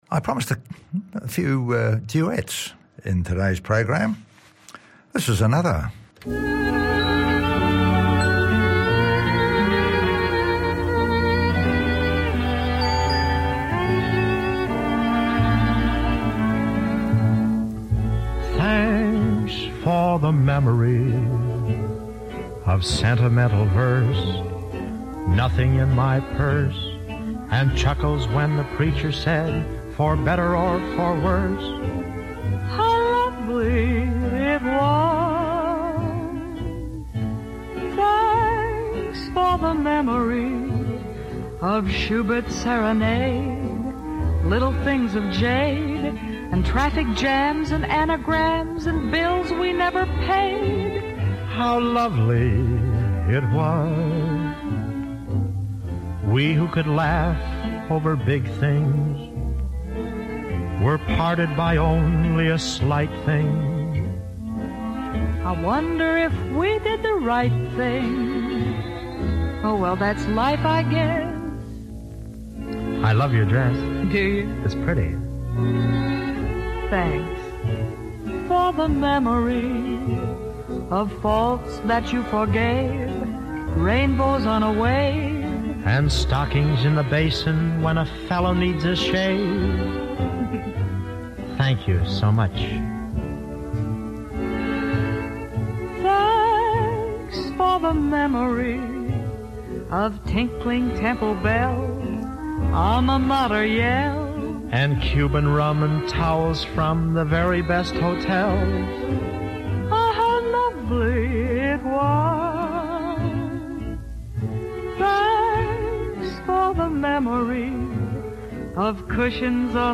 popular music from pre rock & roll eras